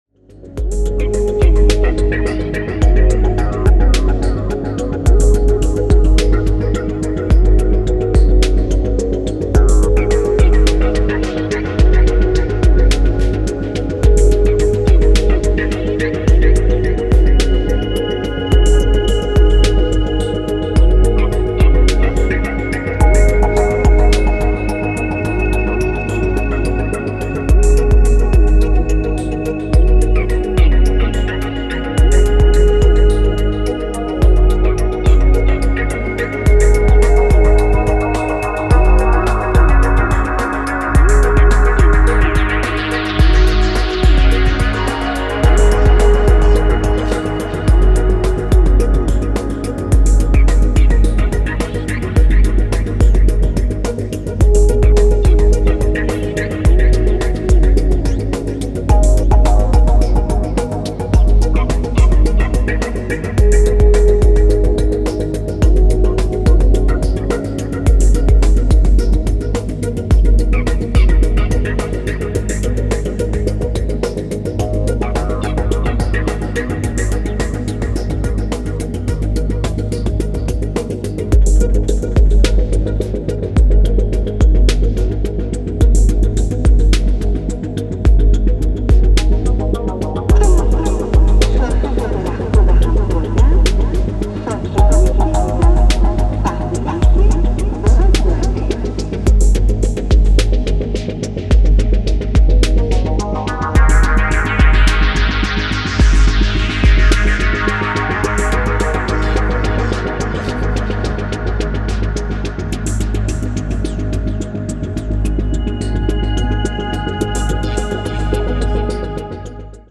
Downtempo Dub Techno